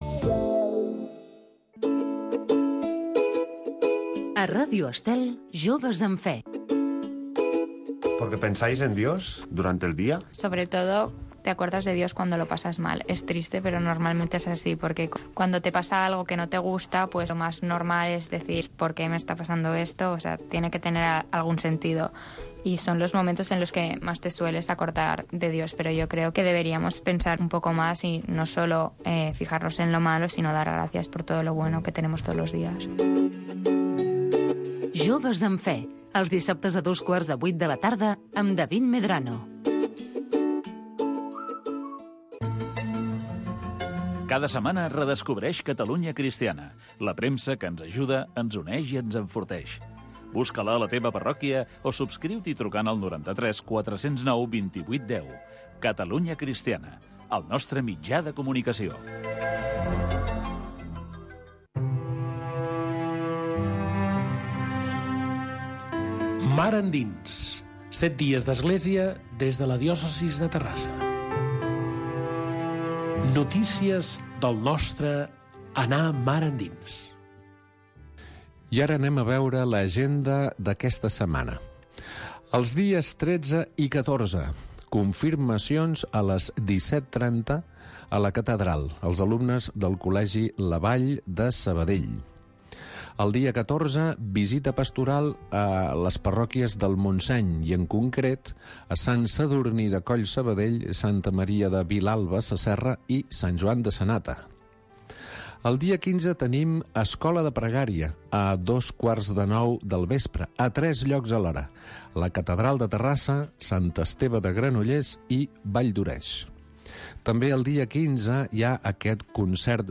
Mar endins. Magazine d’actualitat cristiana del bisbat de Terrassa.